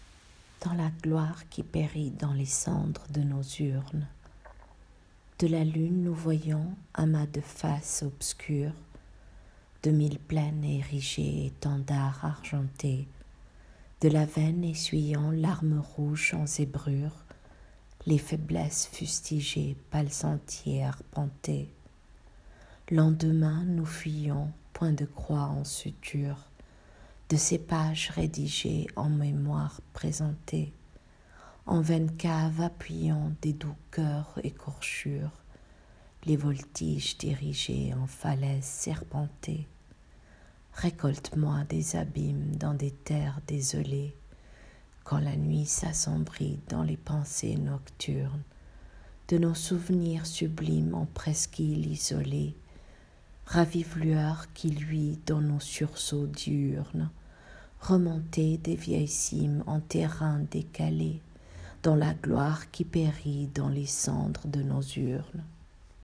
Lecture du poème: